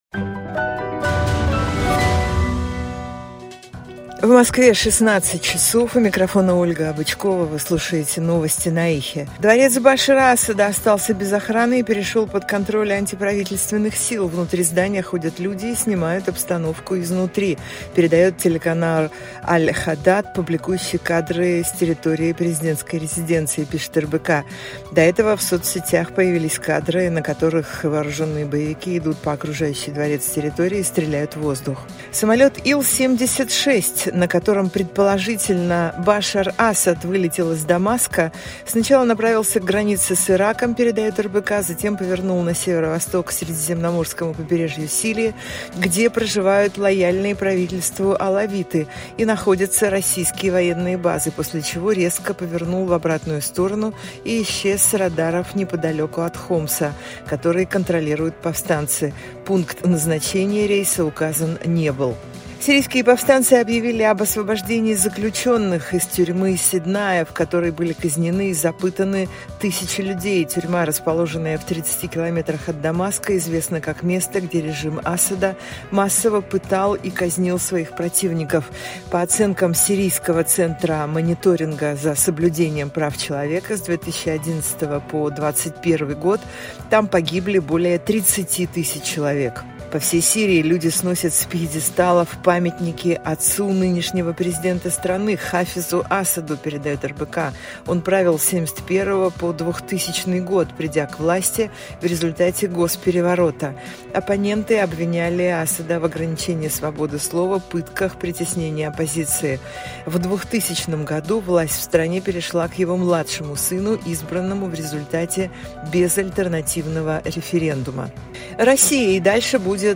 Свежий выпуск новостей